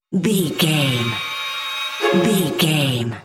Strings Building Up.
Sound Effects
In-crescendo
Ionian/Major
C#
ominous
haunting
eerie
viola